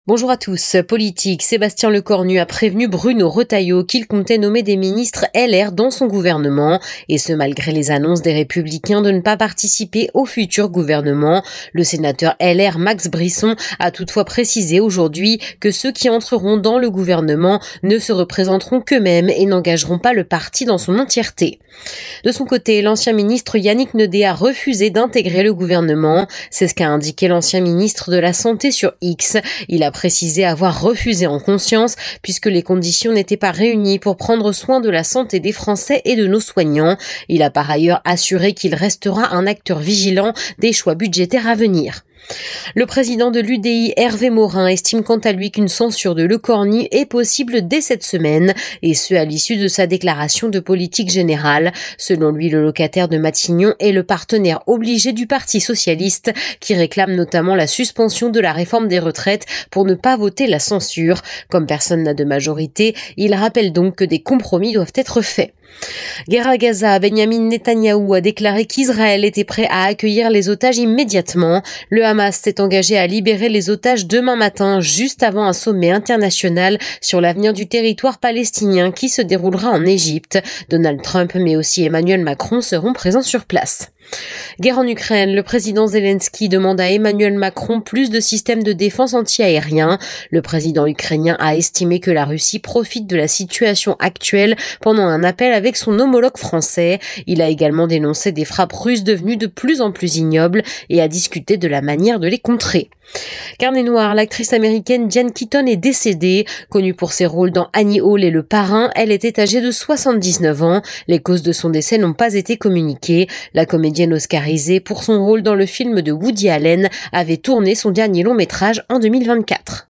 Flash infos 12/10/2024